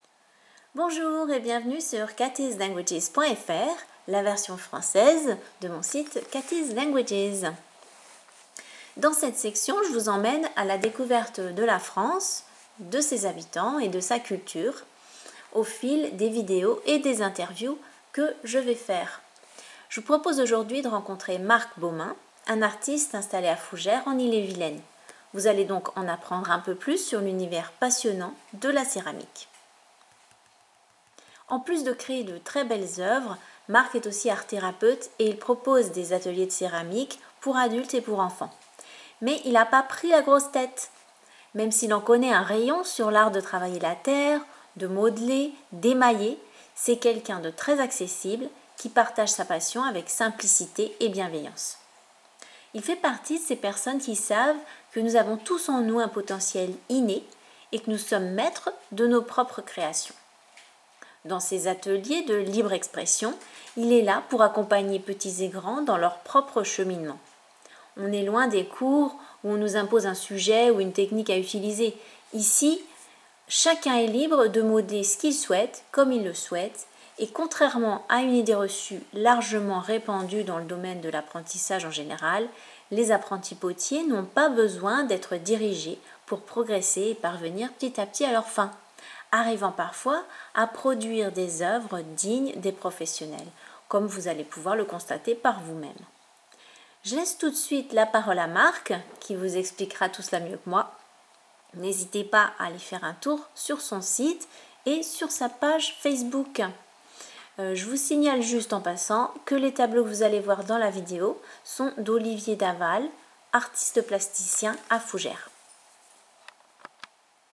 Ecoutez l’émission